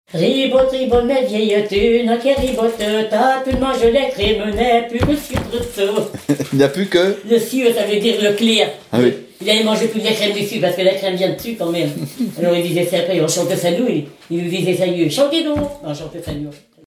Genre brève
Chanteuse du pays de Redon
Pièce musicale éditée